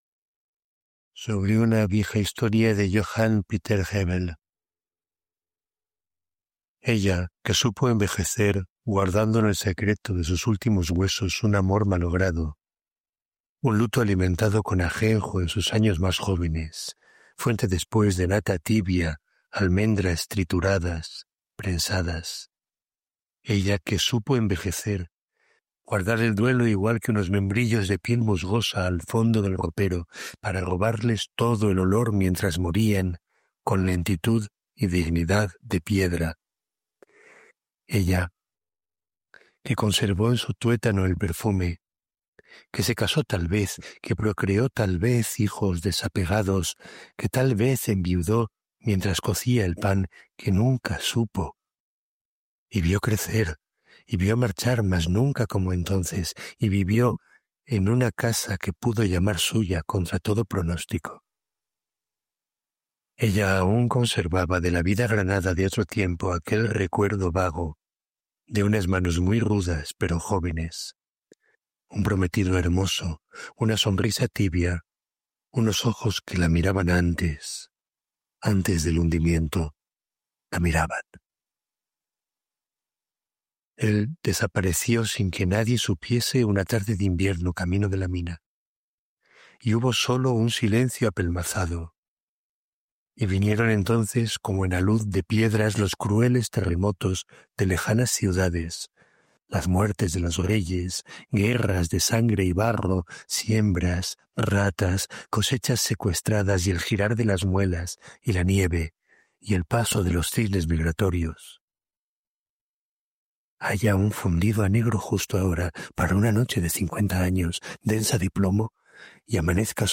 En la voz del autor